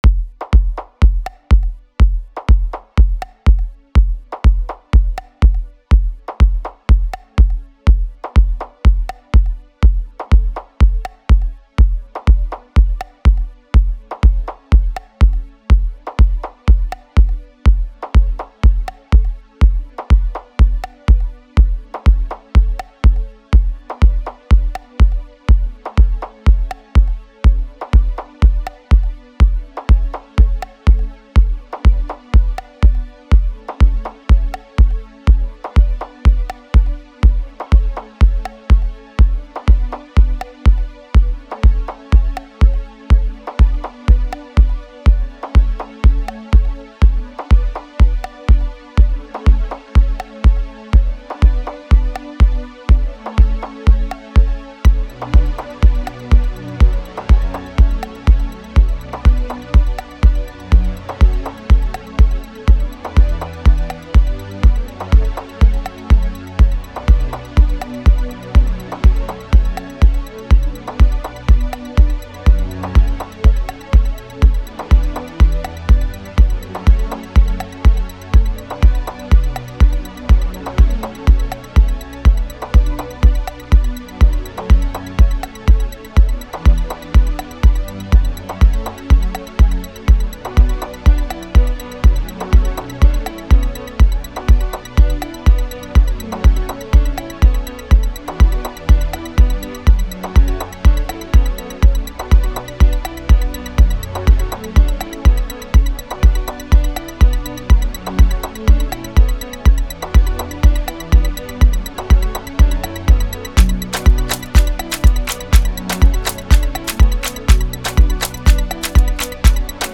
08:13 Genre : House Size